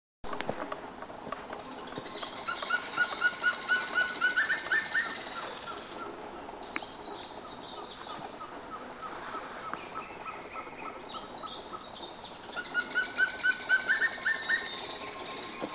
Bailarín Verde (Piprites chloris)
Nombre en inglés: Wing-barred Piprites
Localidad o área protegida: Ruta Provincial 2 camino a Saltos de Moconá
Condición: Silvestre
Certeza: Observada, Vocalización Grabada